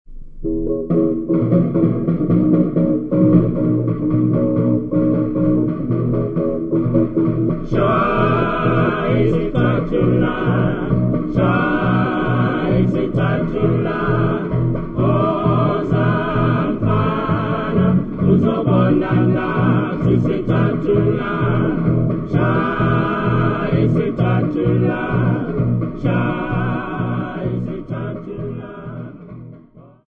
Cape inkspots of Grahamstown
Folk music--Africa
field recordings
A topical song accompanied by guitars and drums .